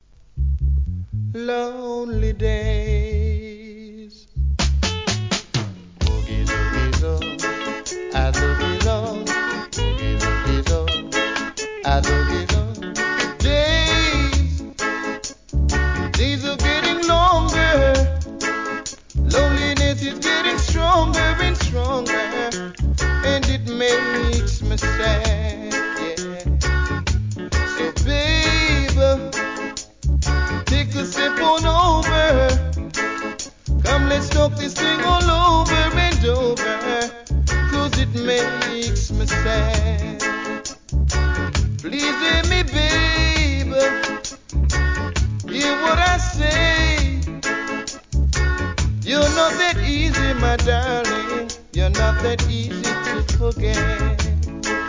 REGGAE
後半DUB接続!!